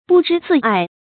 不知自爱 bù zhī zì ài
不知自爱发音